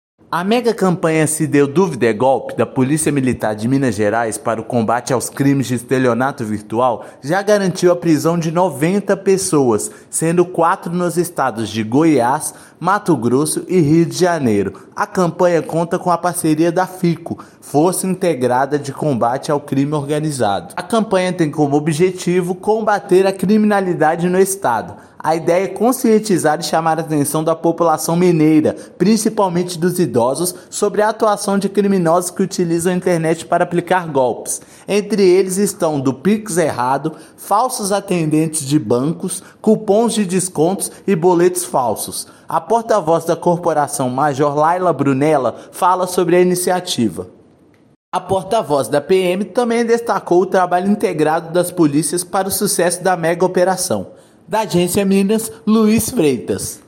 Operação faz parte da megacampanha publicitária da Polícia Militar voltada ao combate dos estelionatos virtuais. Ouça matéria de rádio.